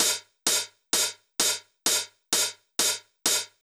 CLF Beat - Mix 9.wav